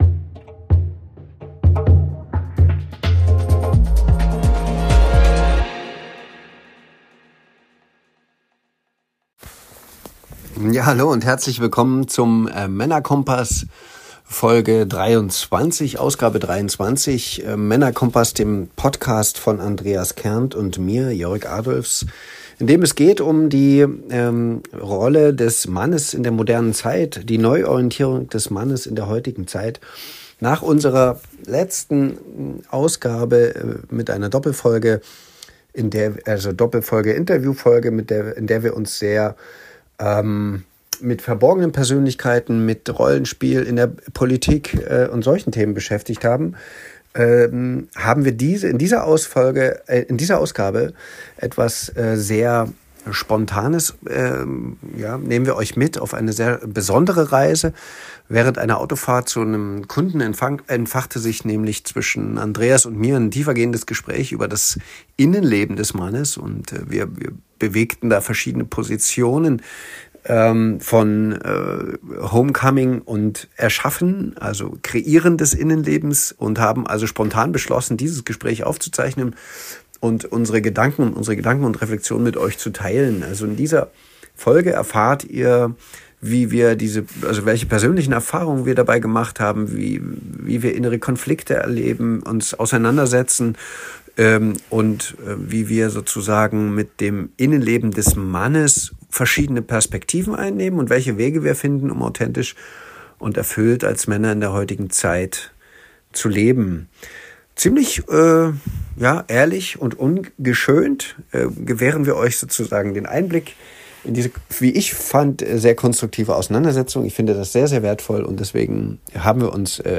Auf einer Autofahrt zu einem Kunden entstand ein spontanes Gespräch, das wir kurzerhand aufgezeichnet haben. Dabei reflektieren wir über persönliche Erfahrungen, innere Unterschiede und evtl. Konflikte und die Suche nach authentischer Männlichkeit in der heutigen Zeit.